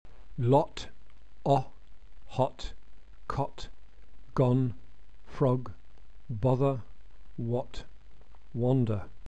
Lax vowels are always short